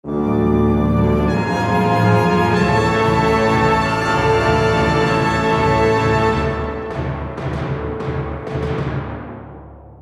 A 10 second orchestral stinger at 192 bpmD minor to A major.